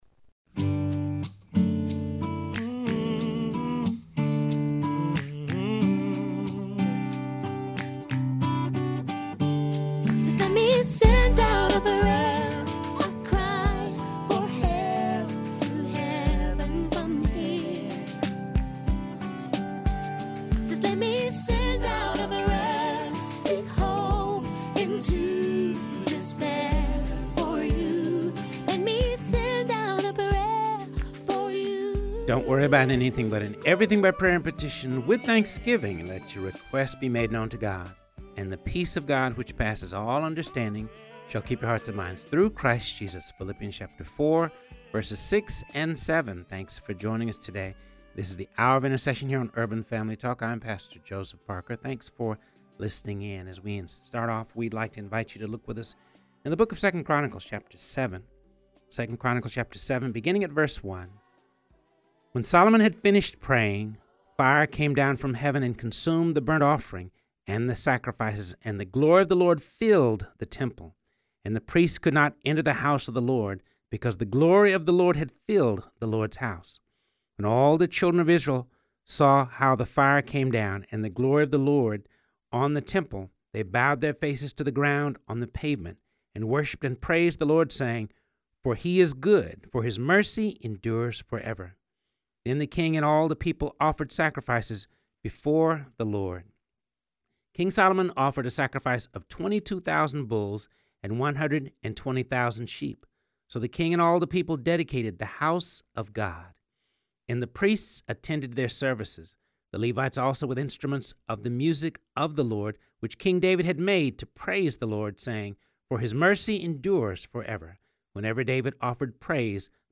In-studio guest